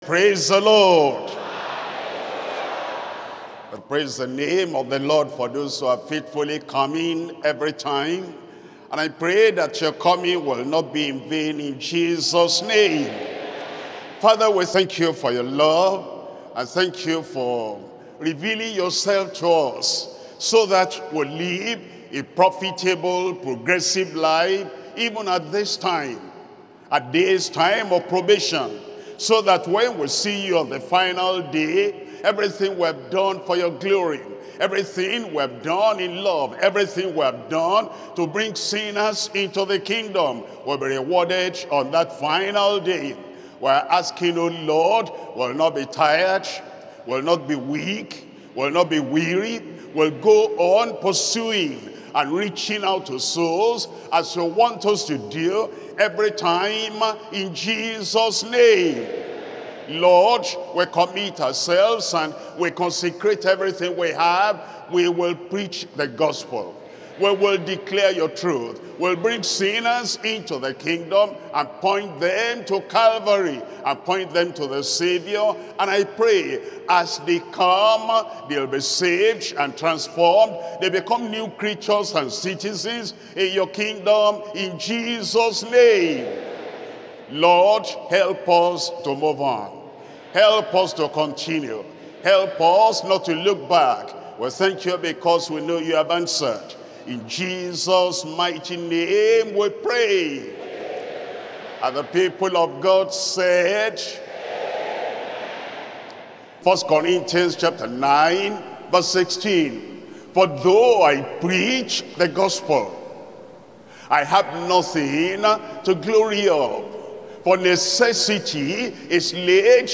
Sermons - Deeper Christian Life Ministry
2025 Workers Training